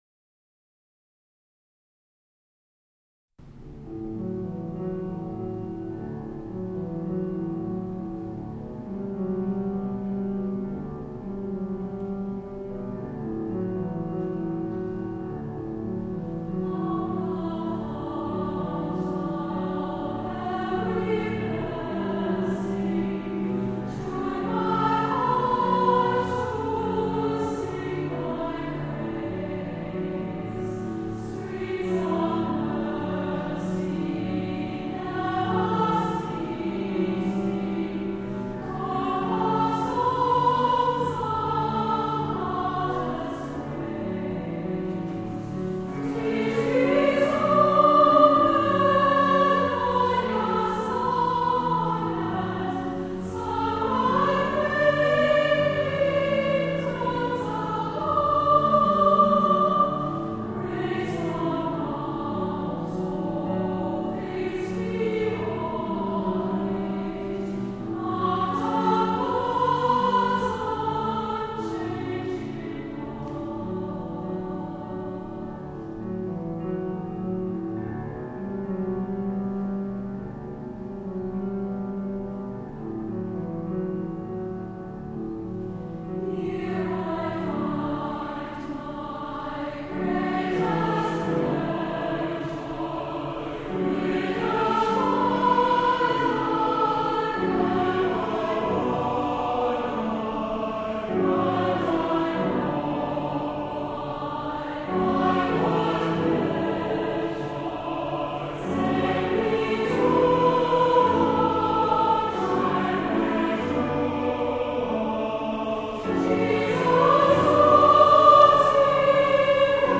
Come Thou Font of Every Blessing | St. Louis Church Choir